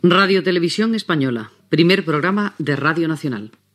Indicatiu com a RTVE, Primer Programa de Radio Nacional.